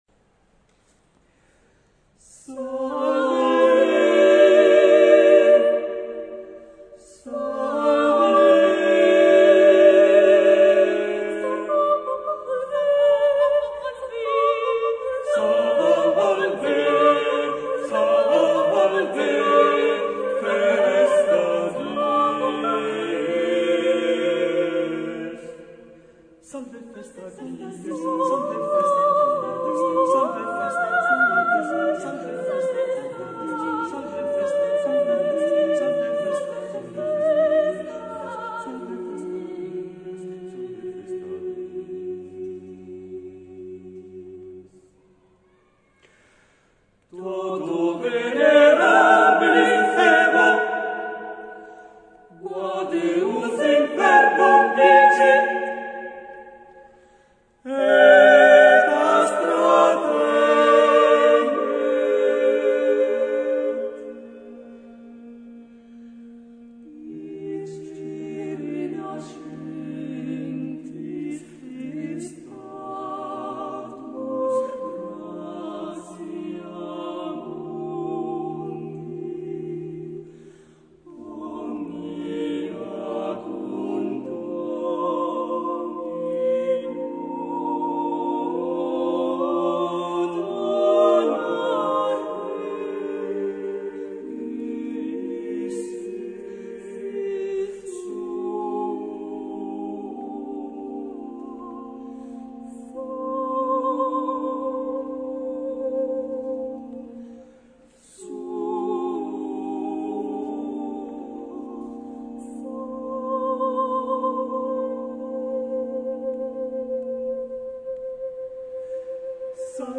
Mottetto a quattro voci (SATB) (Eseguito) (pdf) (